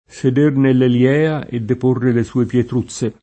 eliea [elL$a] s. f. (stor.) — anche con E- maiusc., inteso come n. pr. (il tribunale supremo d’Atene): seder nell’Elièa e deporre le sue pietruzze [